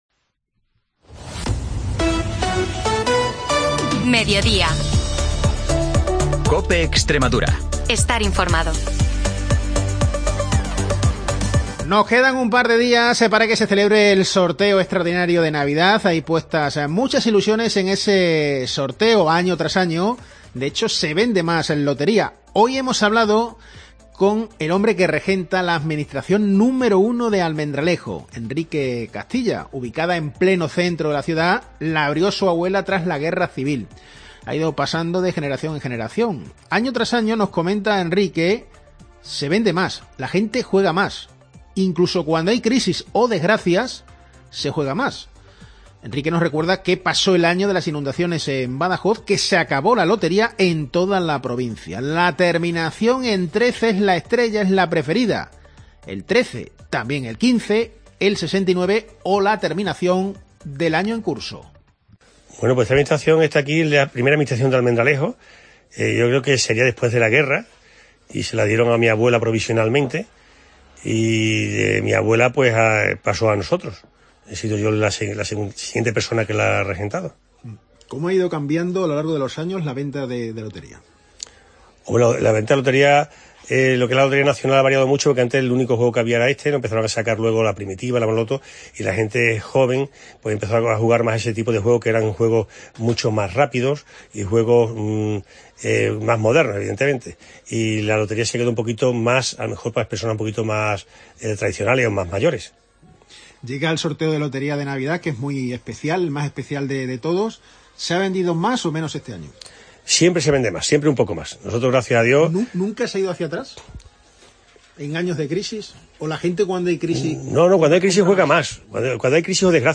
Información y entrevistas